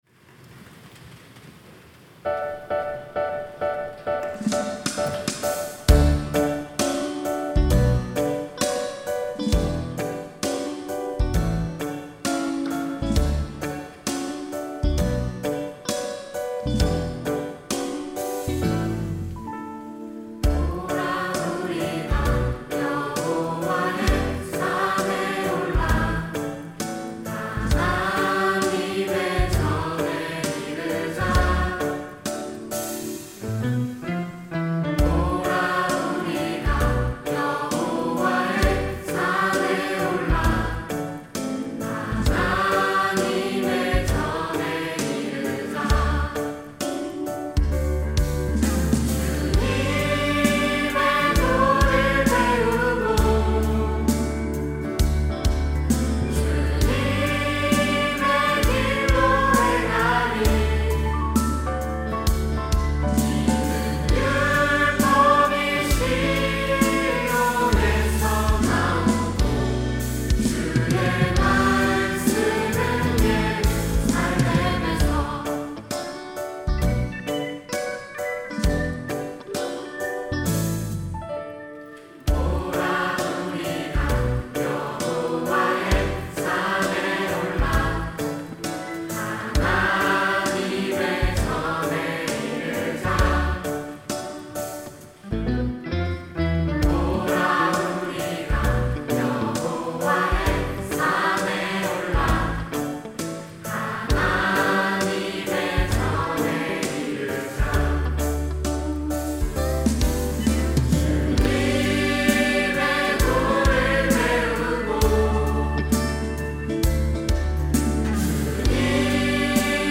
특송과 특주 - 오라 우리가
청년부 4팀